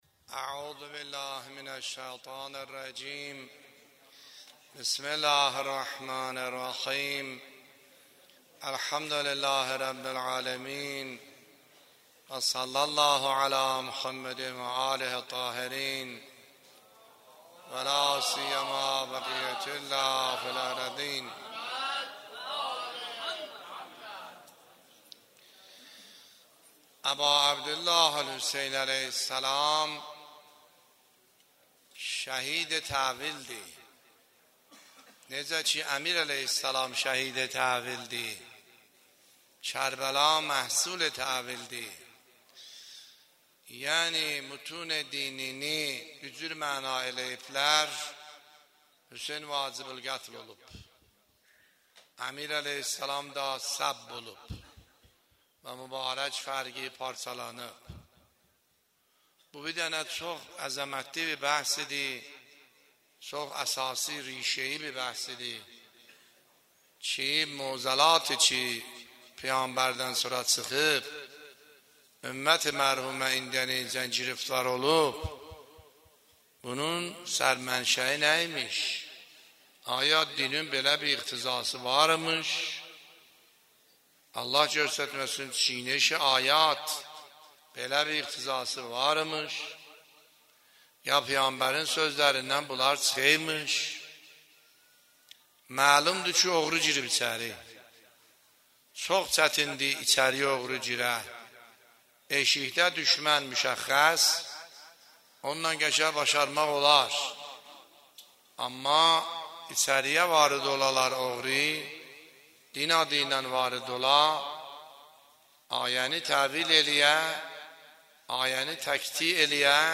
سخنرانی آیه الله سیدحسن عاملی فایل شماره ۲- دهه اول محرم ۱۳۹۷